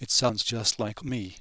CHATR speech database example
Example 1: focus on `me':